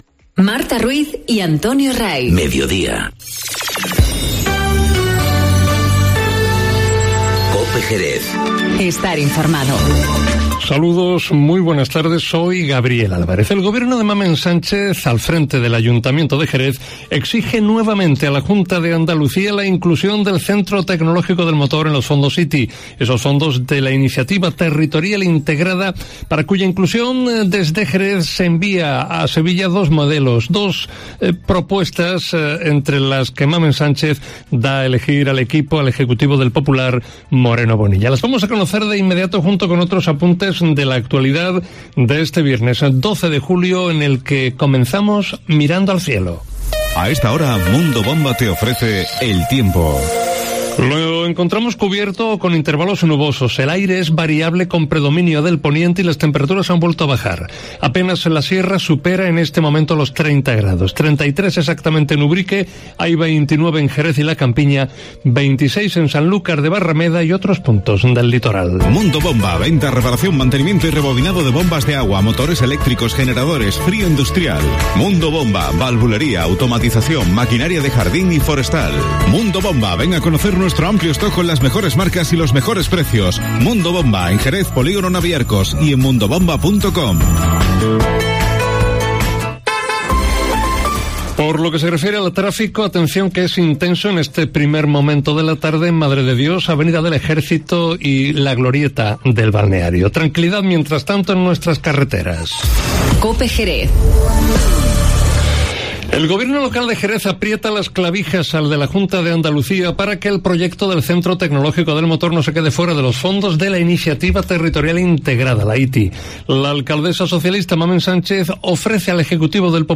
Informativo Mediodía COPE en Jerez 12-07-19